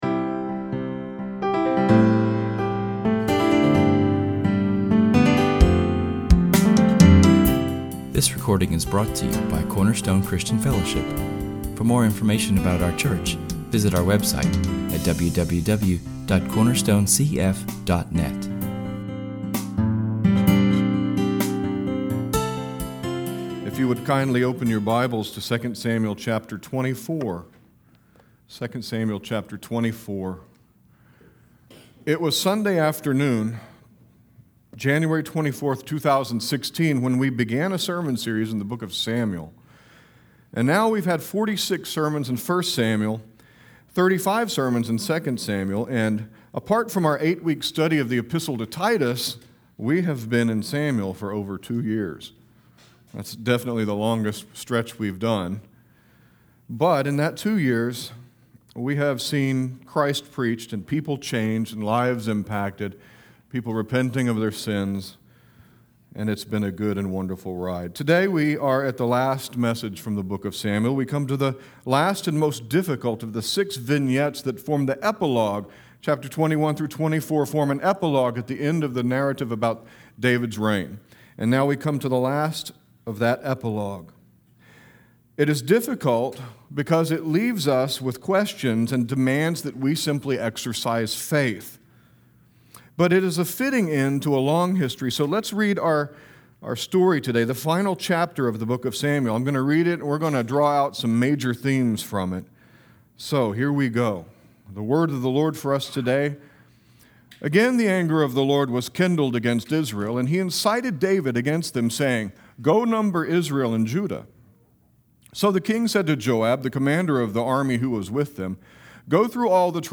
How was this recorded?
We moved our public reading to the end of the sermon as a fitting tribute to the message.